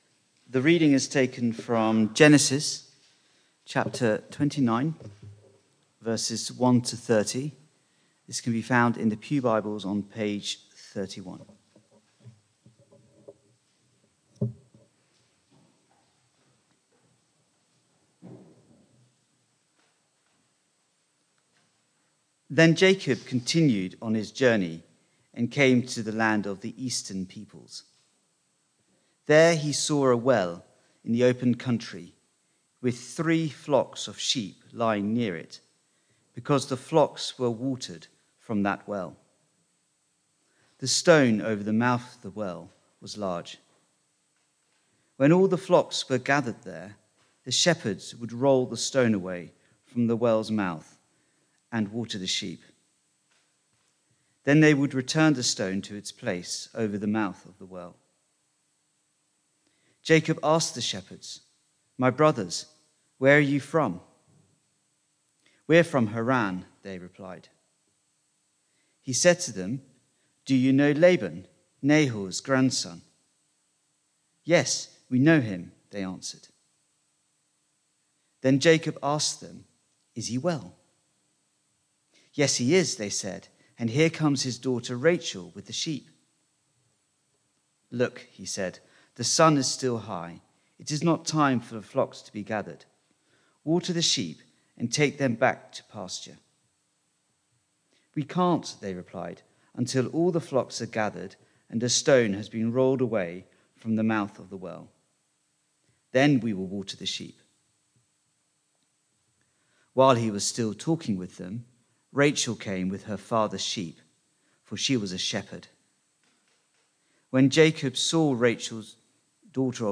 Media for Barkham Morning Service on Sun 08th Jun 2025 10:00
Theme: Sermon